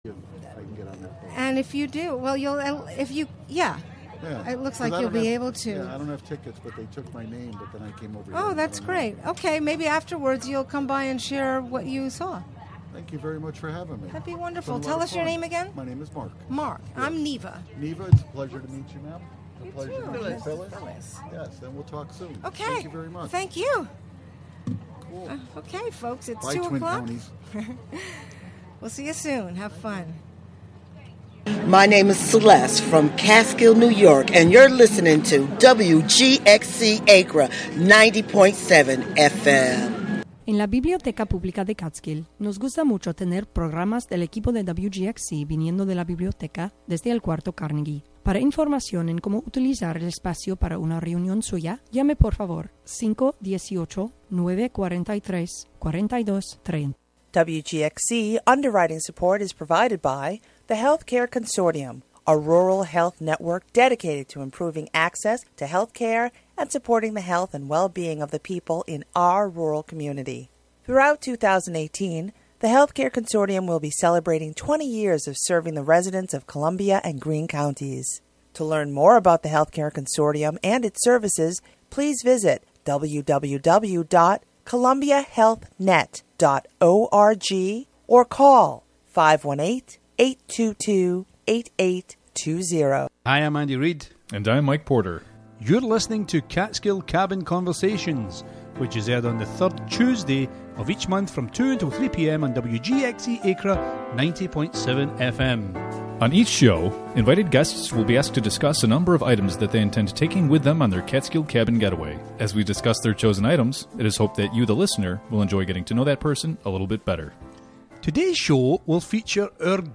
For this special Radio Everywhere! pledge drive broadcast